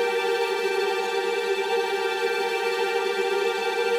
Index of /musicradar/gangster-sting-samples/Chord Loops
GS_TremString-Gmin9.wav